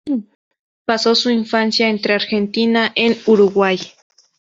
in‧fan‧cia
/inˈfanθja/